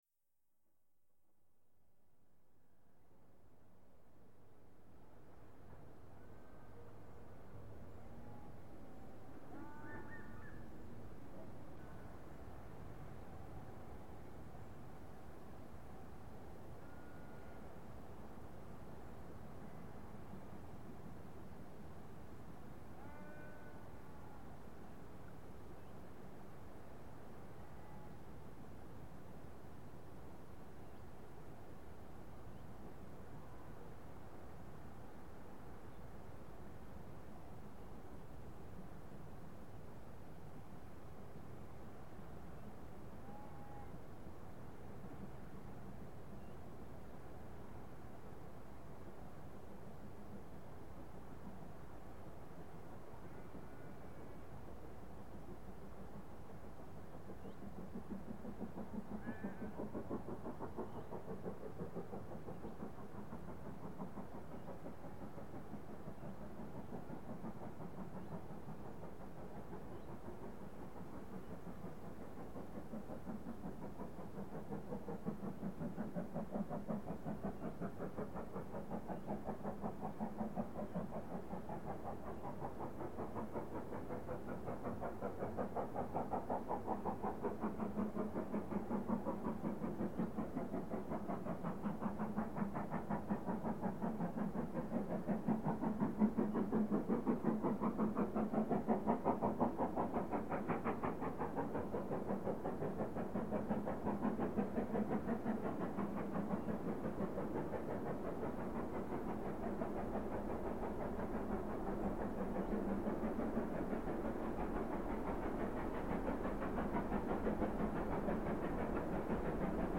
80135 hinter der zweiten Feldwegbrücke bei Green End, um 15:03h am 04.08.2000.   Hier anhören: